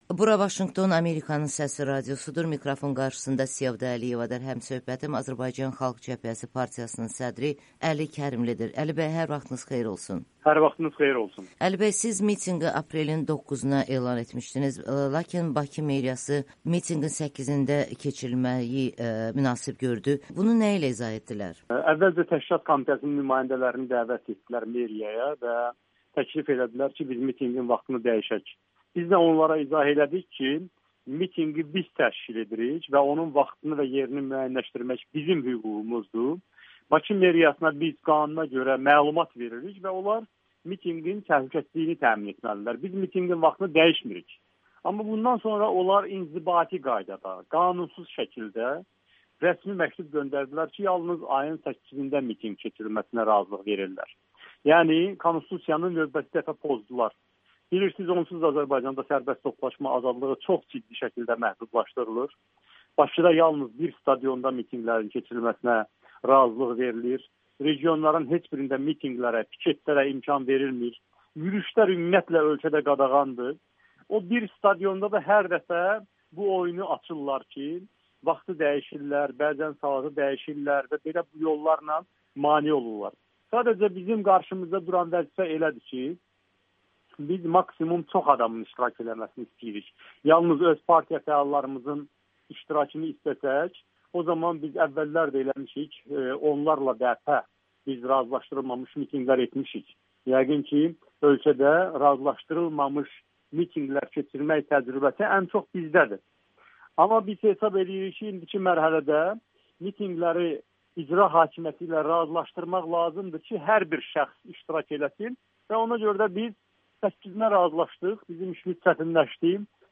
AXCP sədri Əli Kərimli Milli Şuranın mitinqi haqda Amerikanın Səsinə danışır